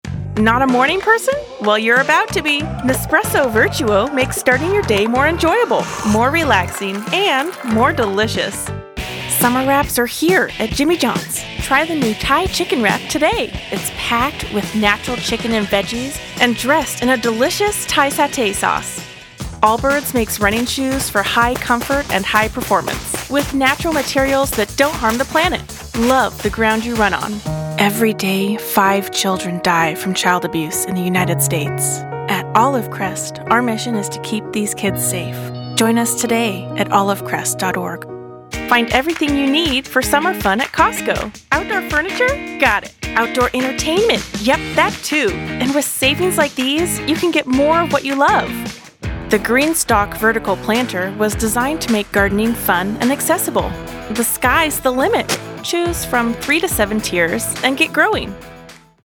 Commercial Demo
Bright & Youthful Female Voice
- Audio-Technica AT2020 cardioid condenser mic with pop filter